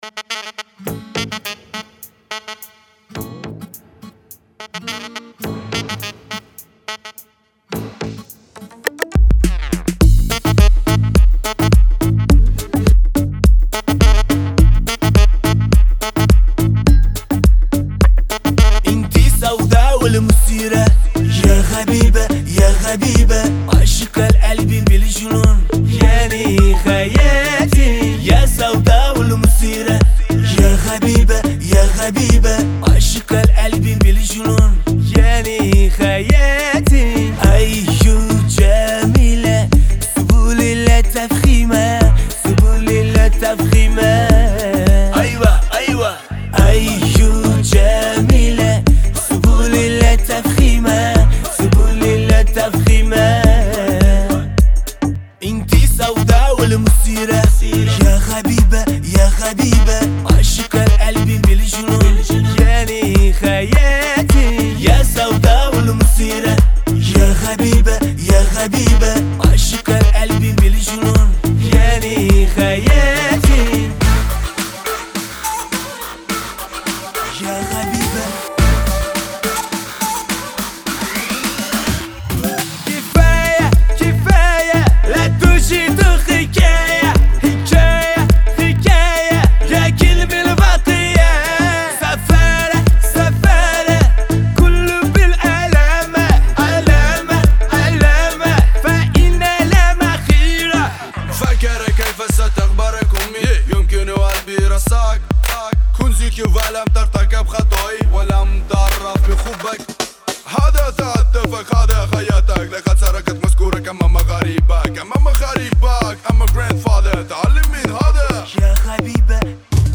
• Жанр: Таджикские Песни